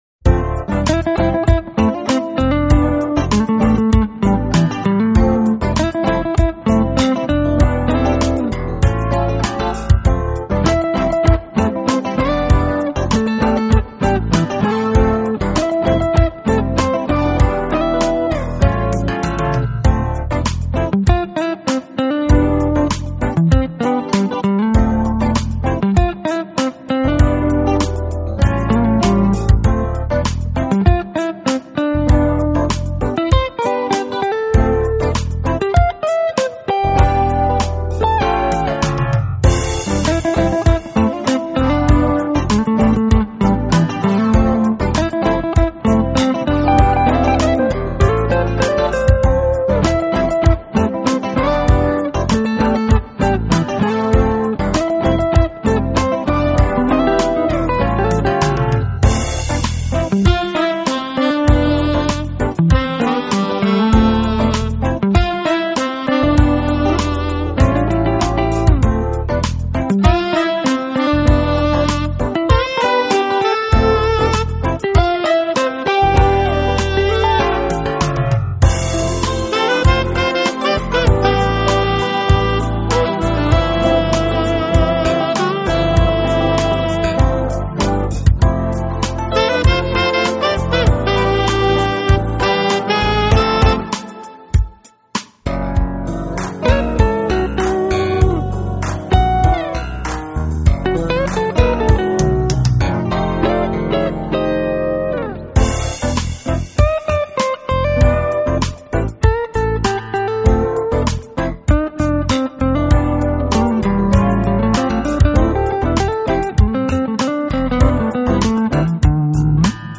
Genre : Jazz
guitars, keyboards, key bass, drum programming
electric piano, piano, B3 Organ, Electric Rhodes
drums, percussion
saxophone
bass
flugelhorn
很好的組合令人心曠神怡。
节奏欢快，旋律优美。